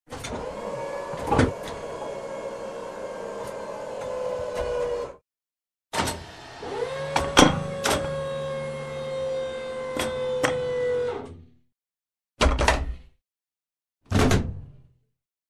Звуки танка
Шум вращения танковой башни